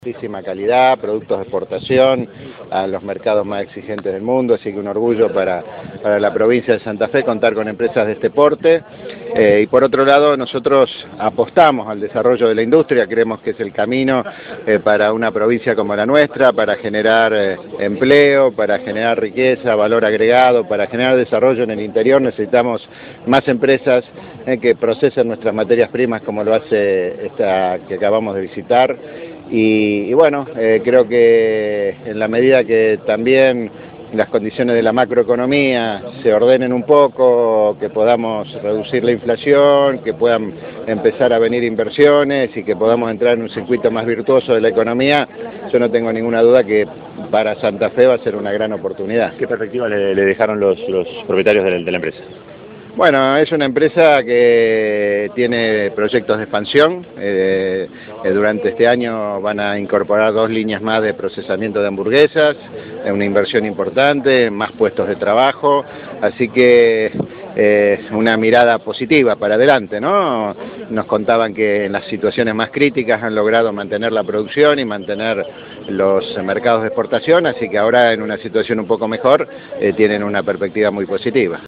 Palabras del gobernador durante la visita